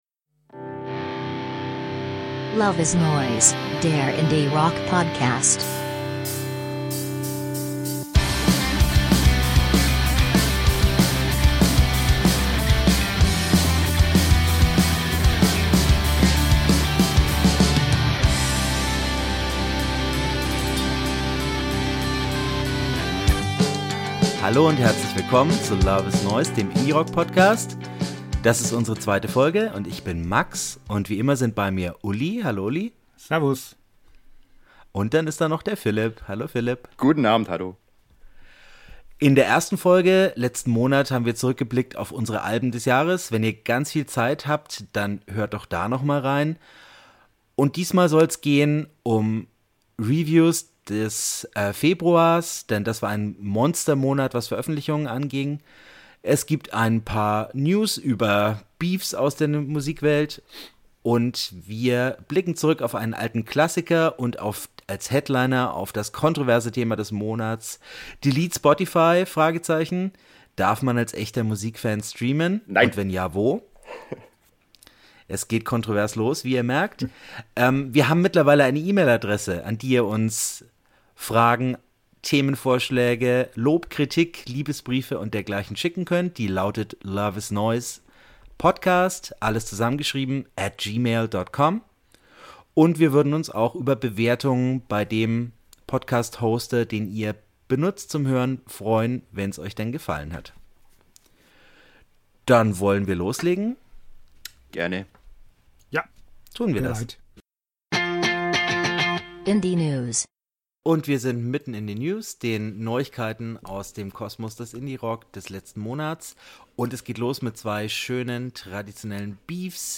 Stets bierselig, kontrovers und gut gelaunt.